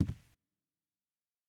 FootstepW2Right-12db.wav